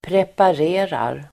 Uttal: [prepar'e:rar]